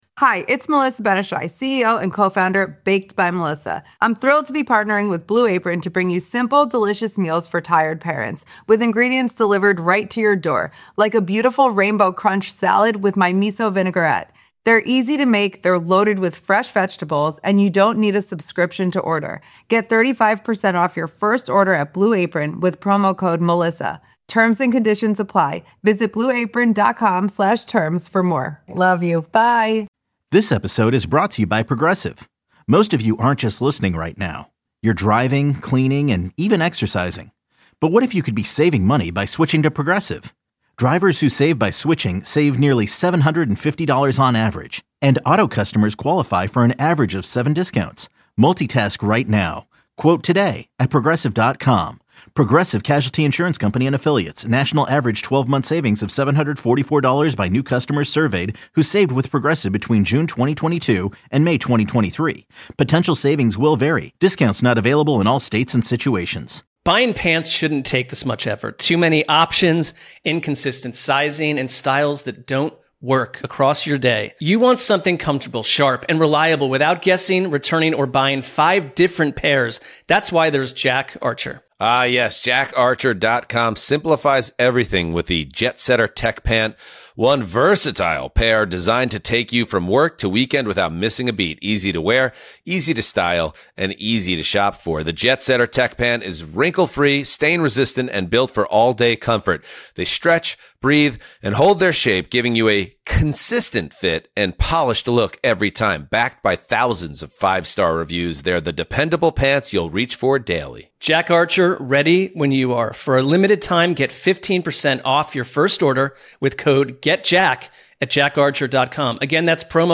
Jets fans also get pulled into the conversation, especially around potential crossover candidates and rivalry tension, as callers weigh in on what a successful Giants staff could look like and why these decisions may define the entire era.